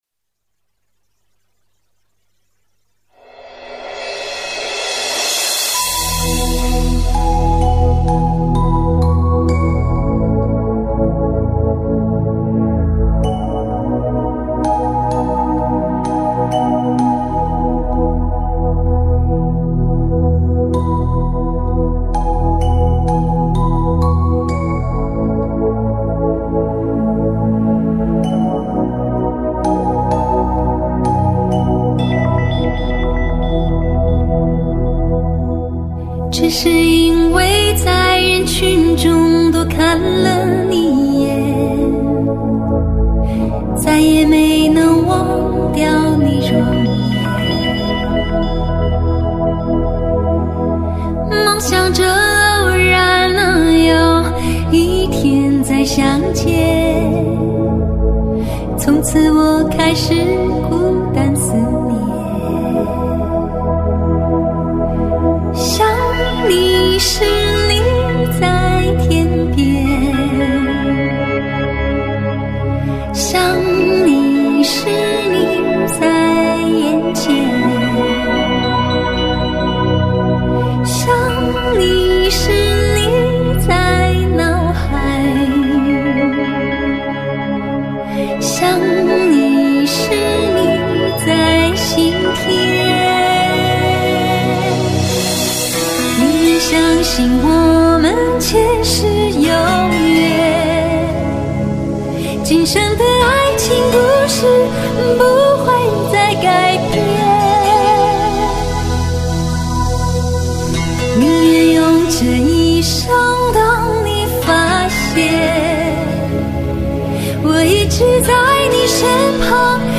本碟低音更紧凑有力,弹性更深,能量感和 冲击力更身临其境！抓轨后码率高达1.41 Mbps，音质相当不错！